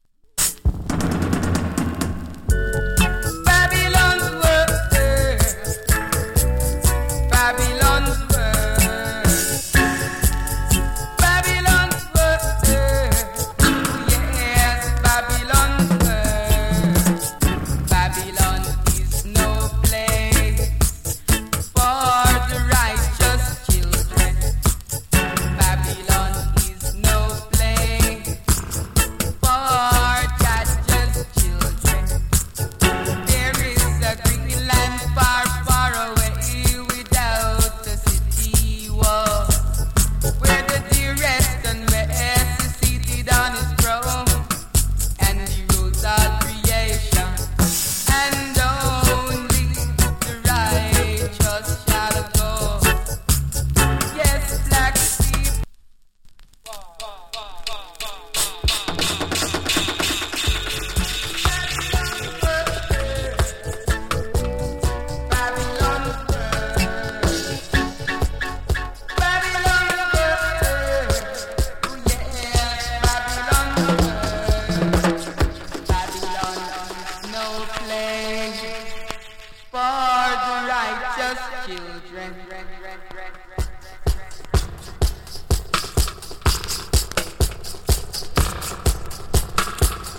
わずかにチリ、ジリノイズ有り。